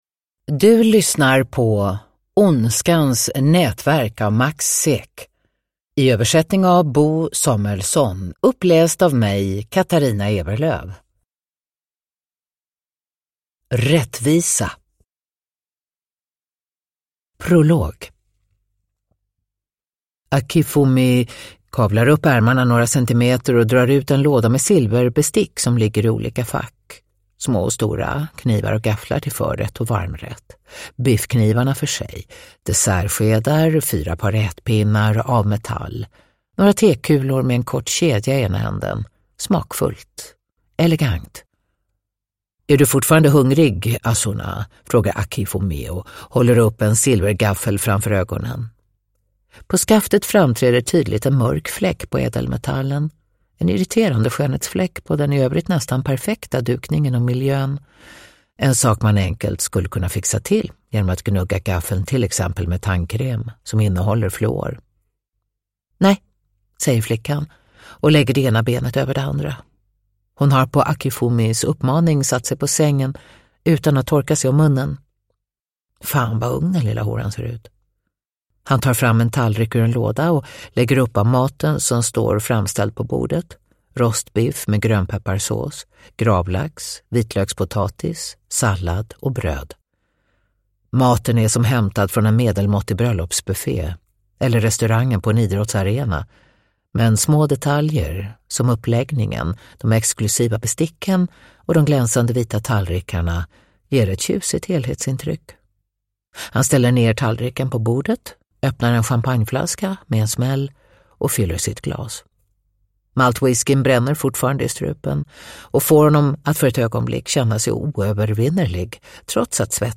Ondskans nätverk – Ljudbok – Laddas ner
Uppläsare: Katarina Ewerlöf